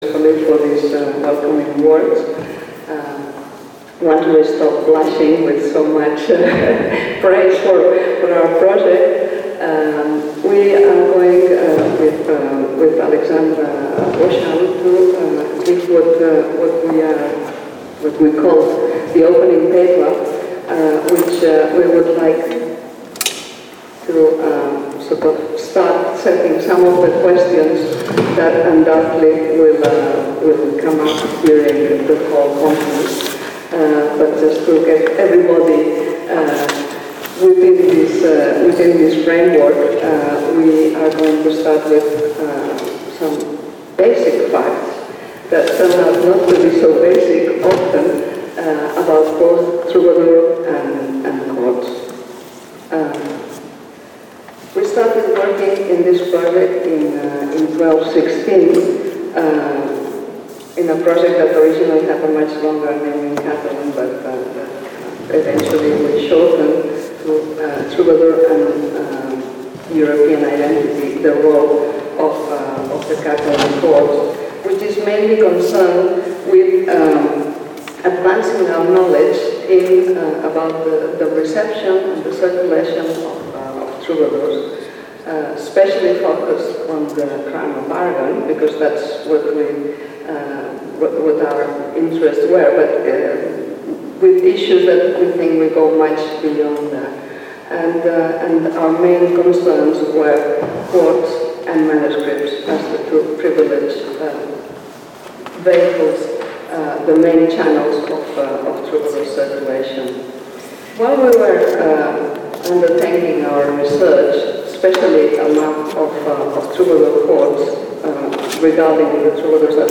Acte de presentació de l'International Conference Courts and Troubadour Culture